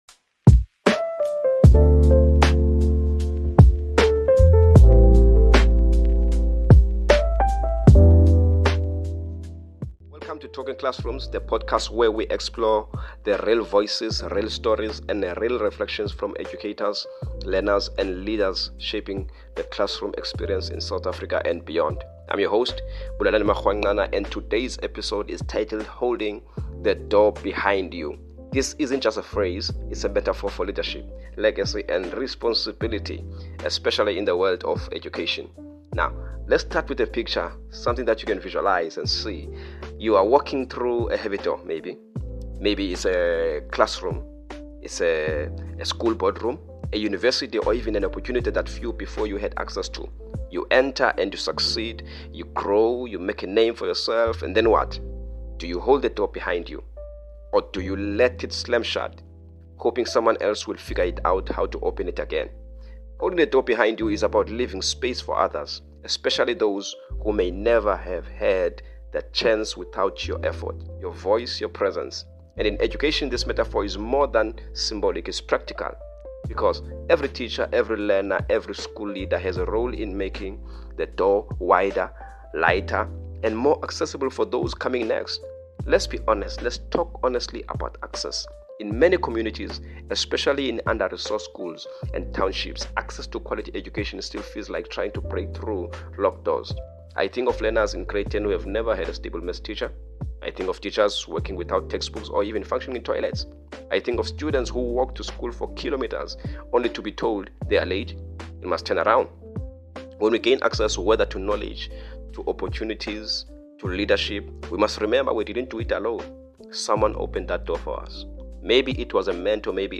In this reflective solo episode